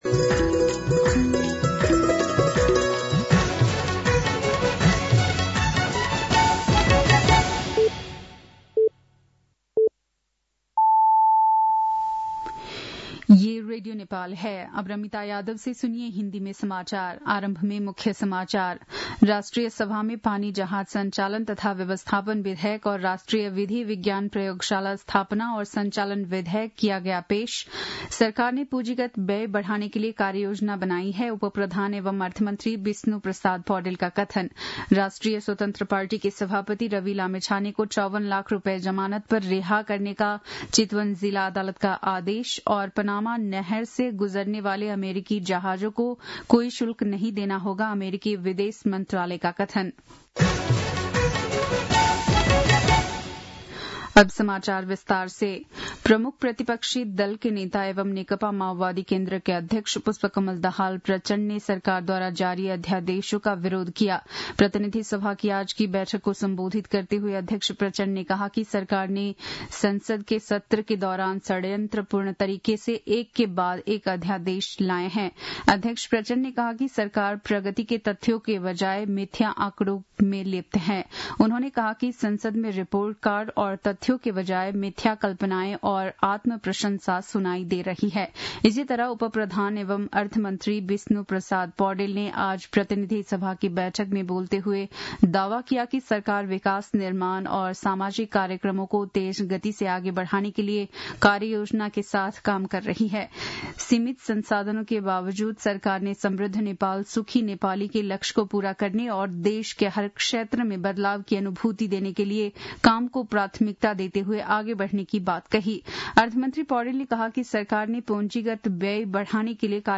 बेलुकी १० बजेको हिन्दी समाचार : २५ माघ , २०८१